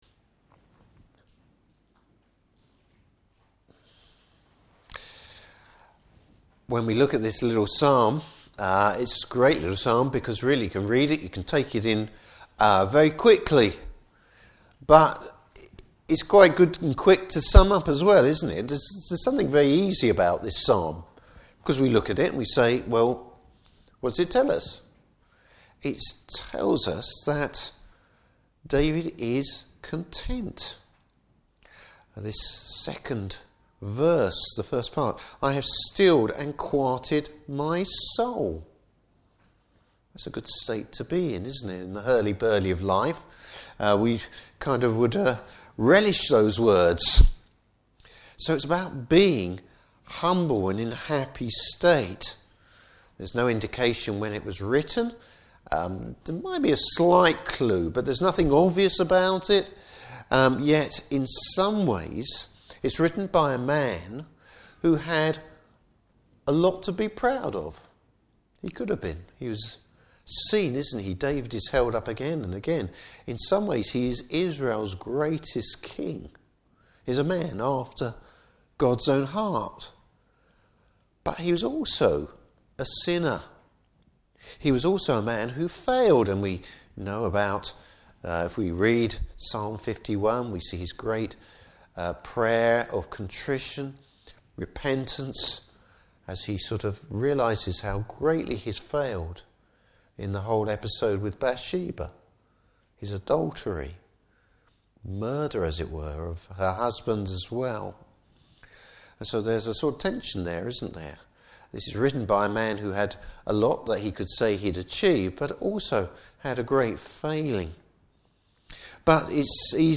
Psalm 131 Service Type: Evening Service Bible Text